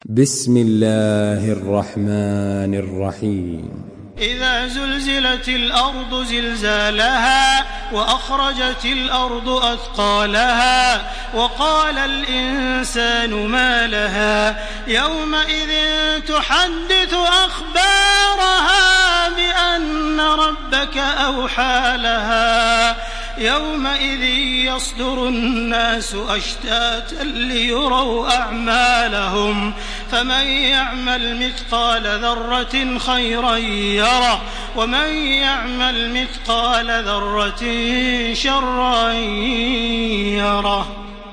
تحميل سورة الزلزلة بصوت تراويح الحرم المكي 1429